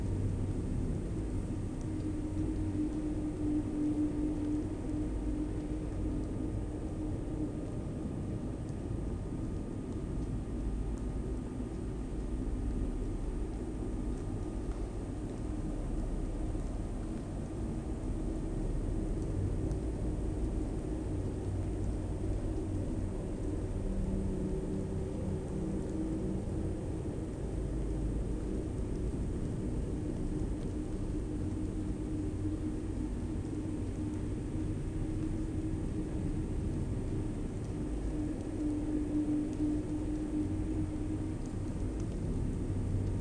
Minecraft Version Minecraft Version latest Latest Release | Latest Snapshot latest / assets / minecraft / sounds / ambient / nether / basalt_deltas / ambience.ogg Compare With Compare With Latest Release | Latest Snapshot
ambience.ogg